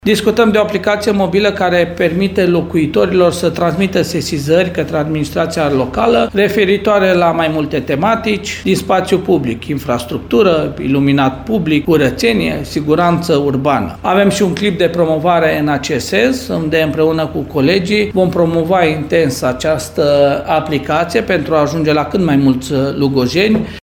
În felul acesta, primăria poate acționa eficient pentru rezolvarea acestor situații, spune primarul Călin Dobra.